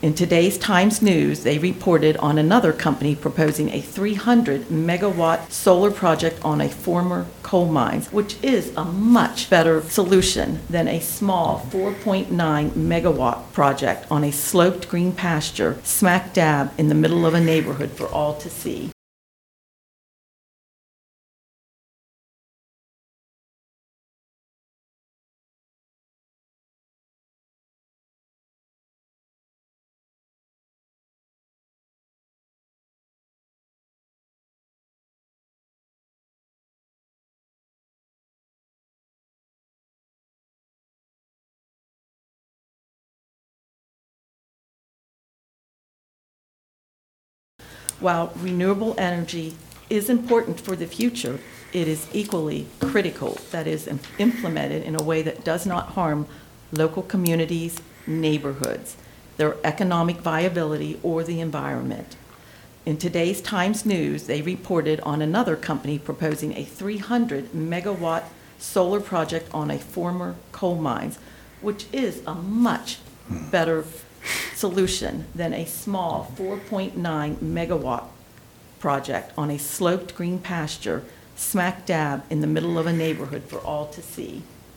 During last night’s Allegany County Commissioner’s meeting, residents of Cash Valley showed concern regarding the former Helmstetter farm becoming a solar project.